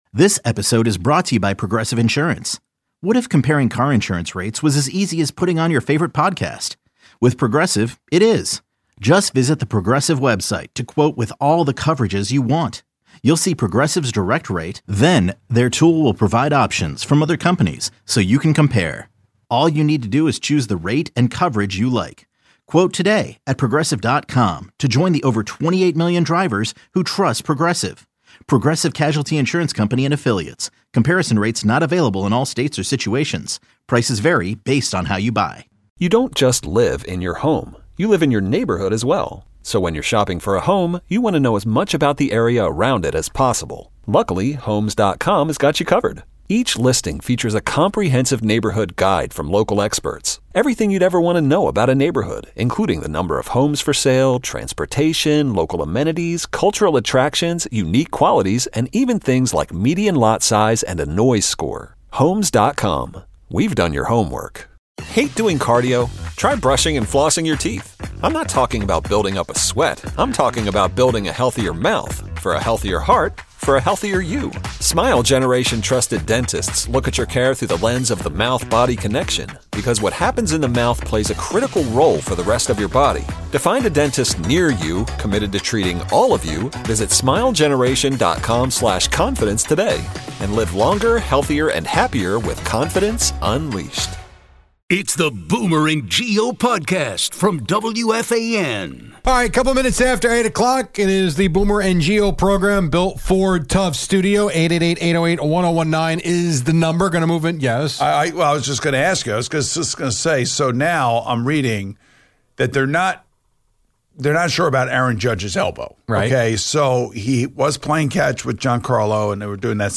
Judge's elbow raises questions, while Stanton's bat is needed. Retired callers express uncertainty about their newfound free time.